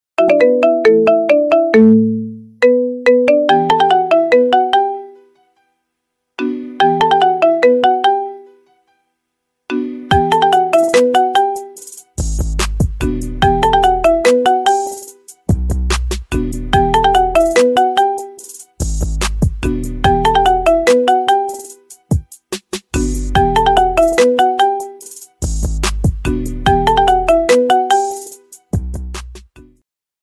Рингтоны Без Слов » # Рингтоны Ремиксы